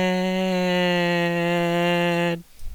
The singer starts out holding a tune, but soon dips below that tone.